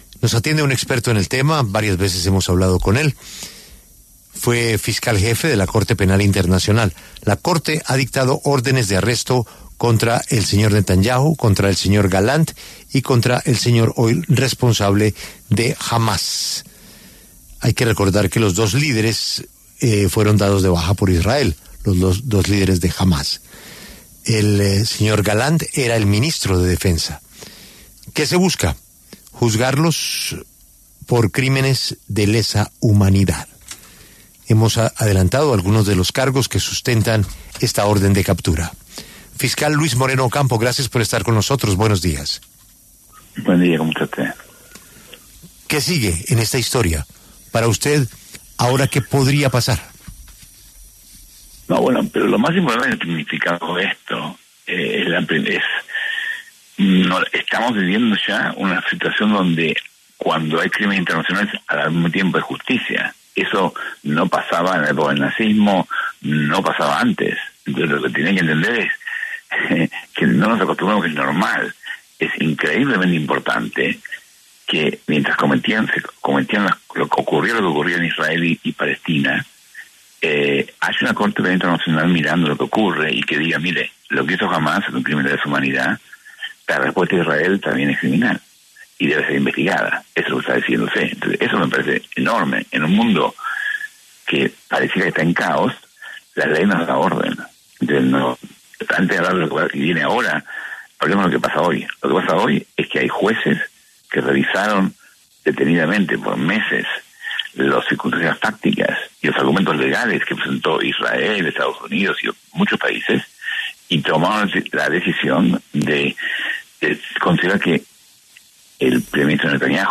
Luis Moreno Ocampo, exfiscal jefe de la Corte Penal Internacional, se refirió en La W a las órdenes de arresto contra Benjamín Netanyahu y Yoav Gallant por crímenes de guerra en Gaza.